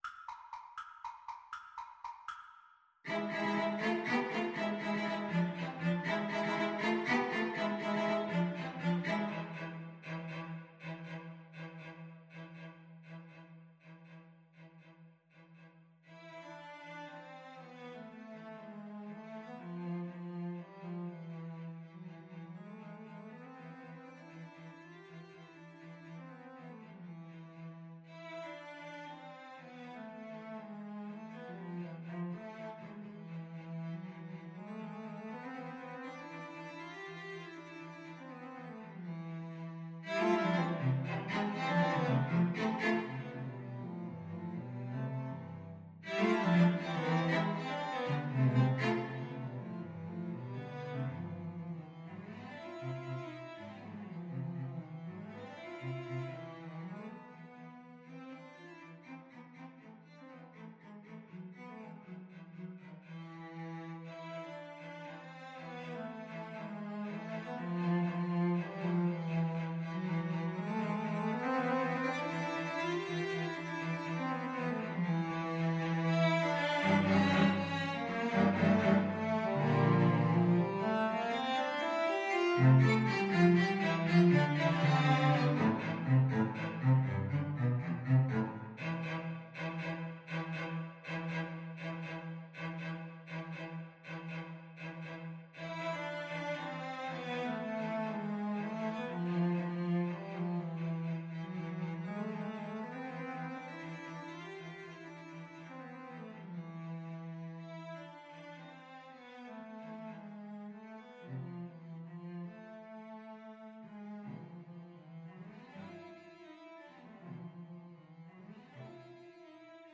3/8 (View more 3/8 Music)
Allegro vivo (.=80) (View more music marked Allegro)
Classical (View more Classical Cello Trio Music)